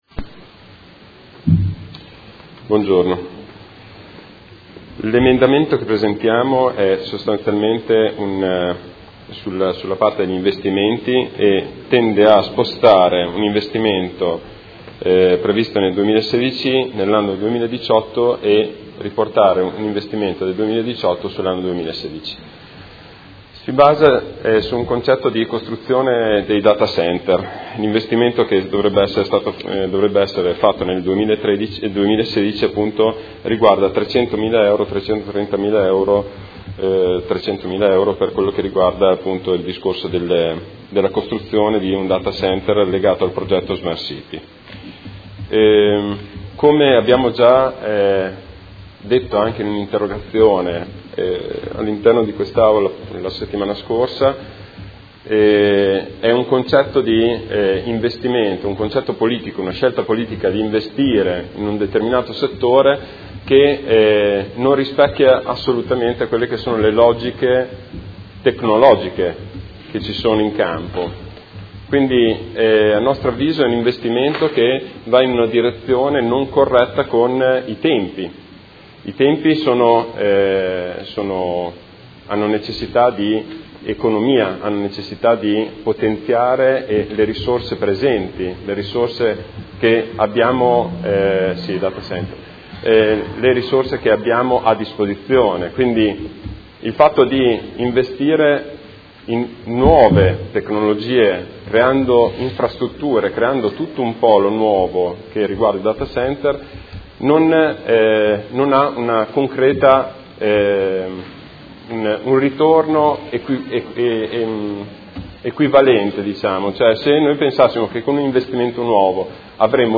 Seduta del 25 febbraio. Approvazione Bilancio: presentazione emendamento Prot. 21361